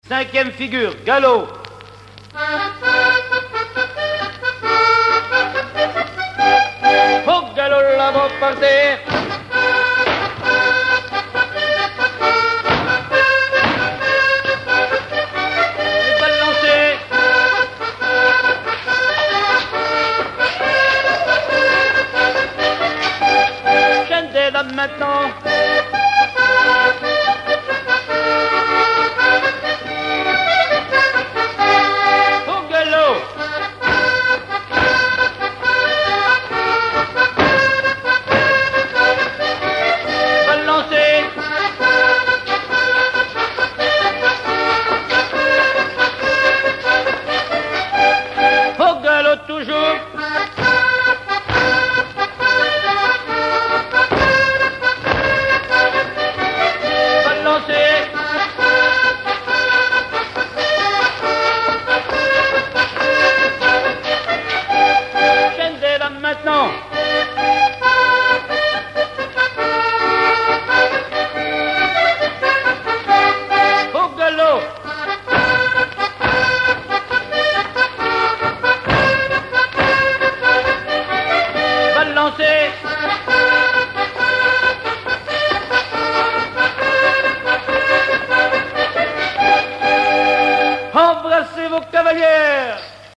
Mémoires et Patrimoines vivants - RaddO est une base de données d'archives iconographiques et sonores.
danse : quadrille : galop
Catégorie Pièce musicale inédite